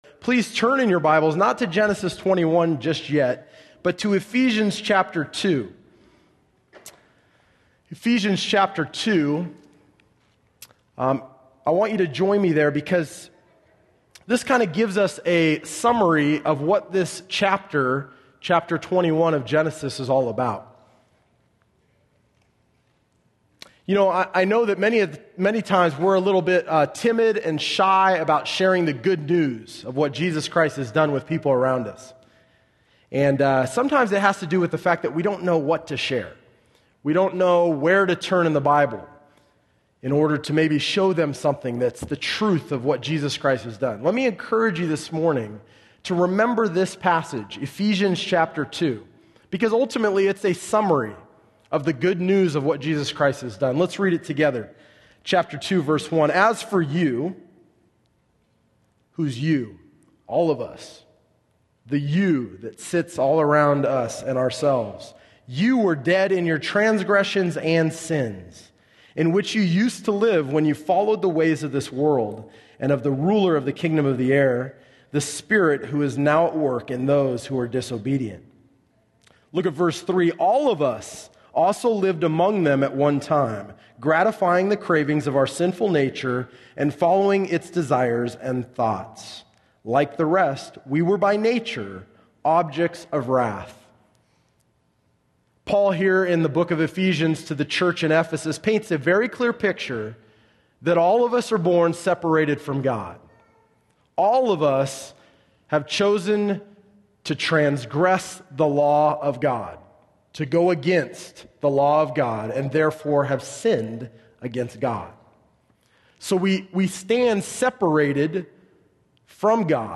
teaches on Genesis 24.